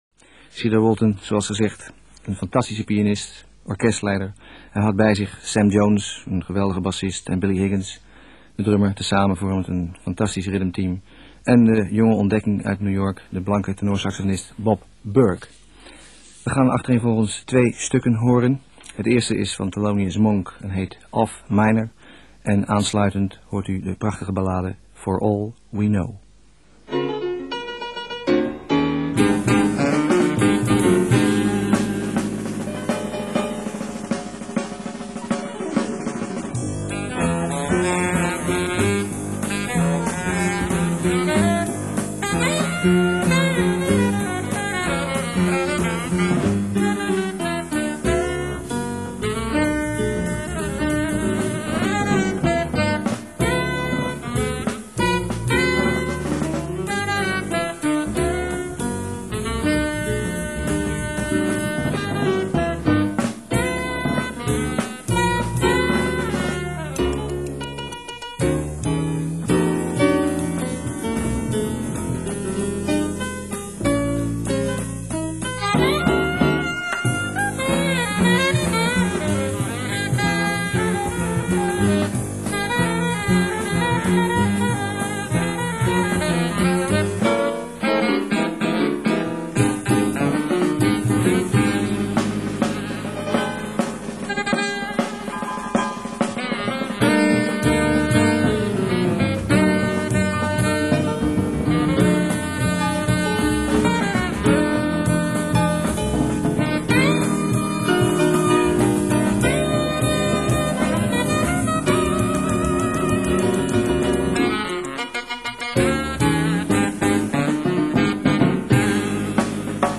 drums
bass
tenor sax